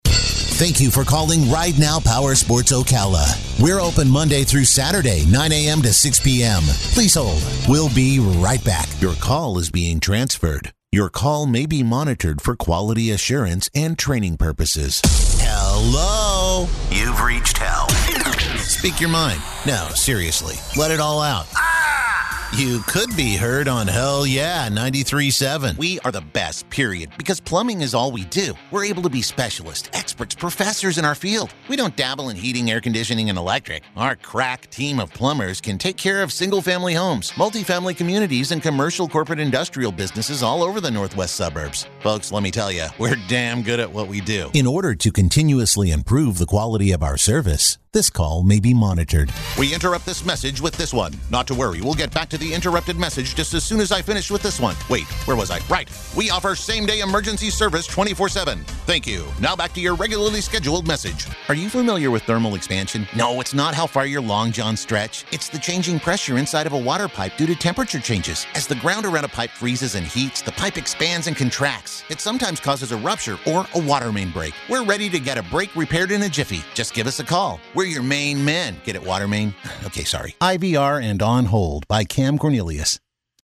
Male
English (North American)
Phone Greetings / On Hold
Phone System Demo
Words that describe my voice are Real, Fun, Friendly.